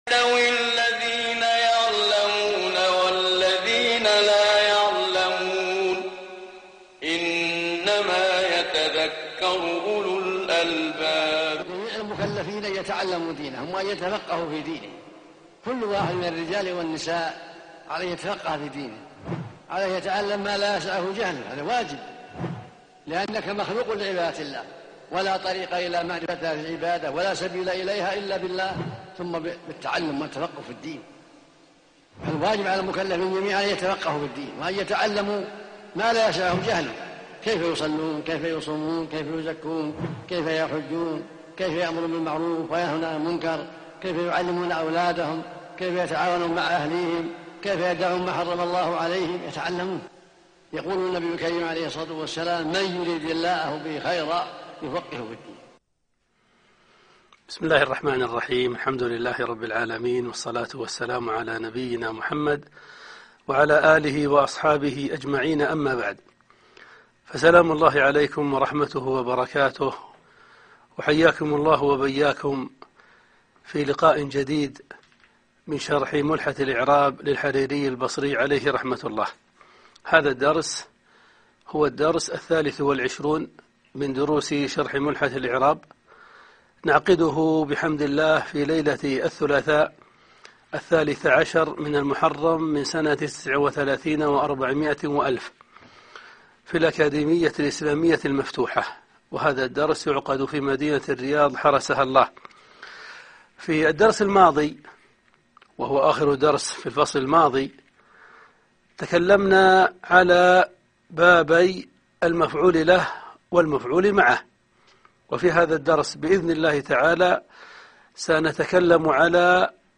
الدرس 1 (ملحة الإعراب 3